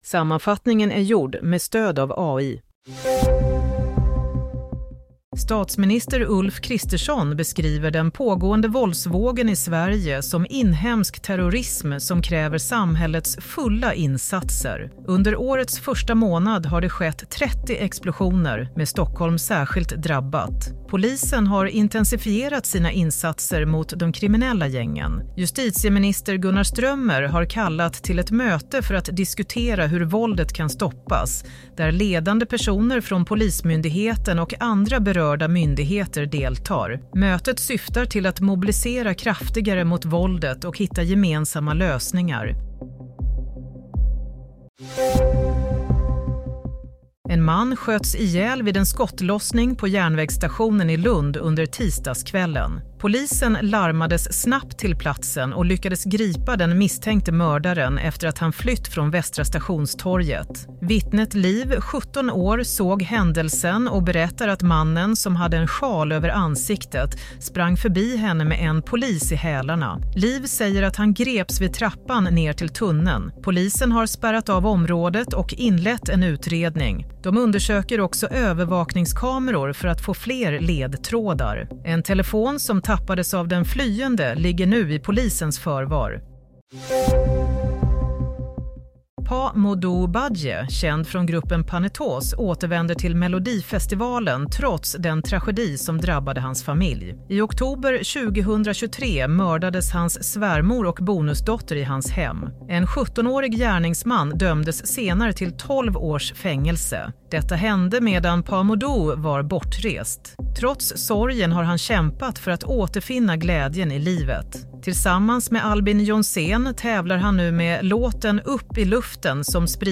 Play - Nyhetssammanfattning – 28 januari 22:00
Sammanfattningen av följande nyheter är gjord med stöd av AI.